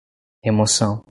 Pronunciado como (IPA) /ʁe.moˈsɐ̃w̃/